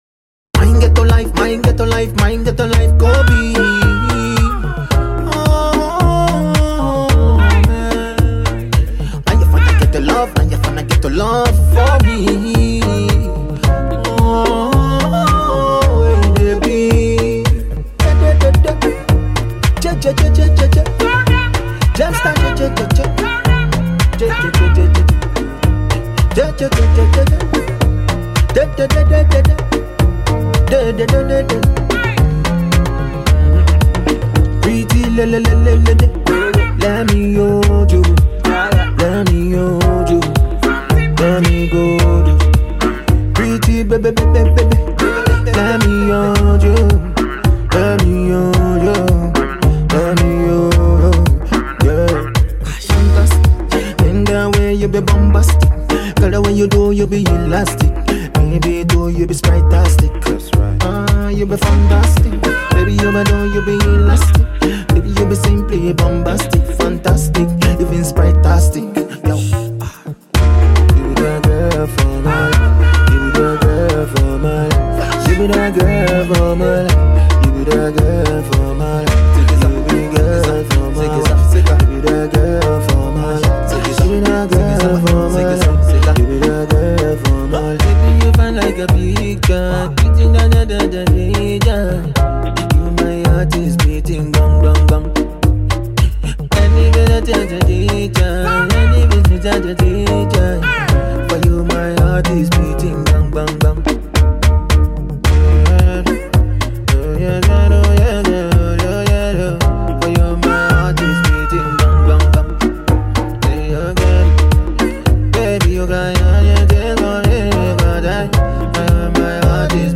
Ghana MusicMusic
lovely afrobeat tune